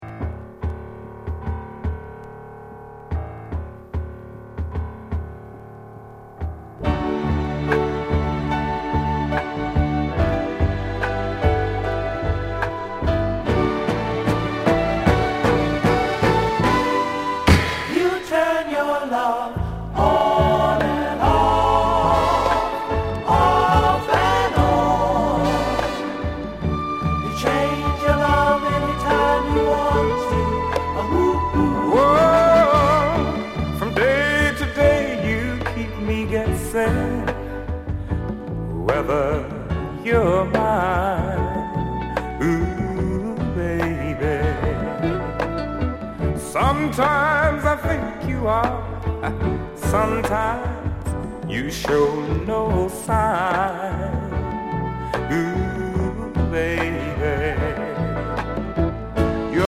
SWEET SOUL